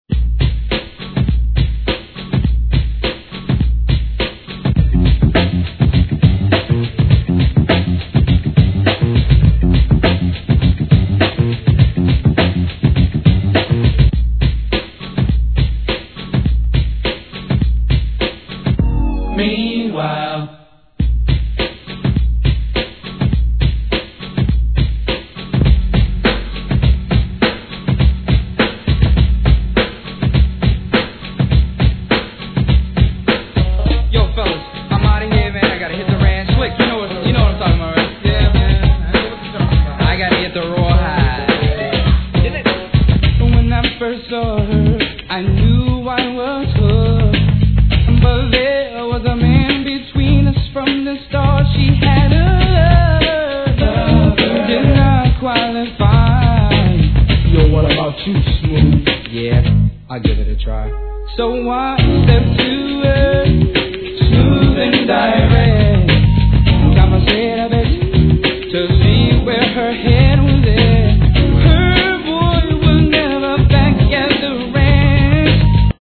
HIP HOP/R&B
童謡のメロディーも飛び出すサウンドは